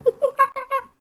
sounds_monkey_02.ogg